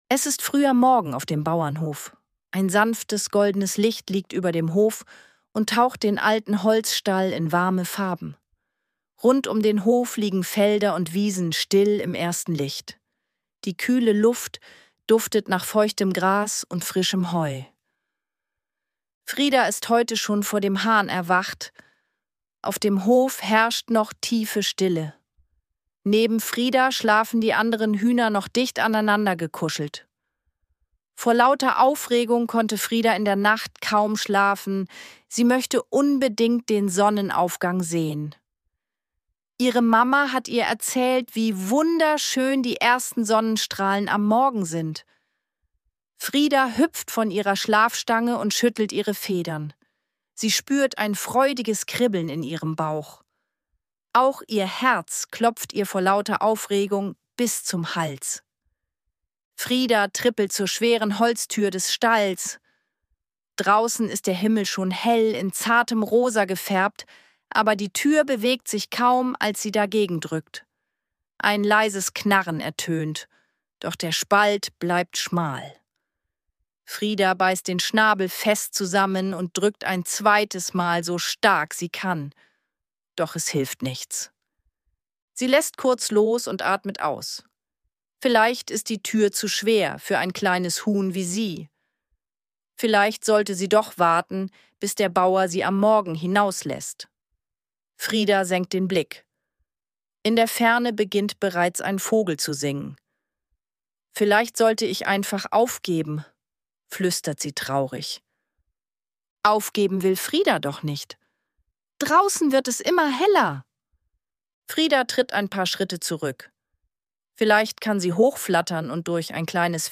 Ruhige Kindergeschichten zum Anhören